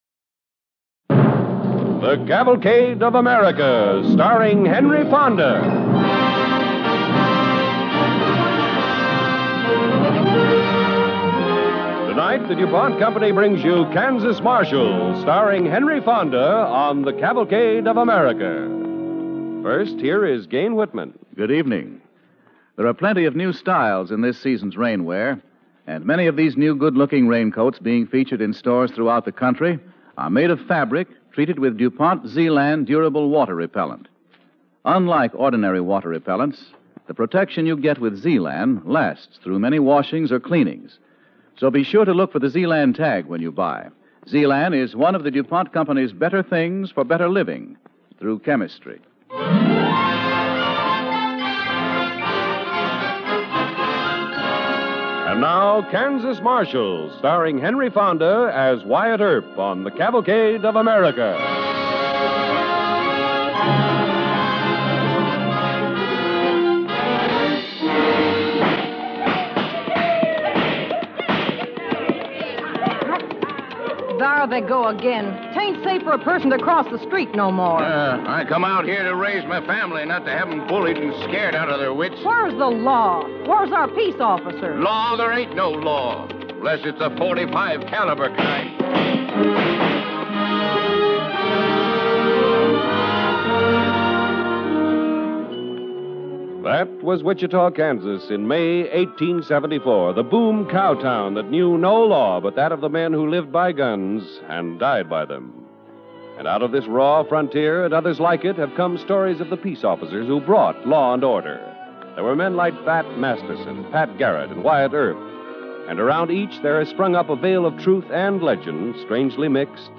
Cavalcade of America Radio Program
The Kansas Marshal, starring Henry Fonda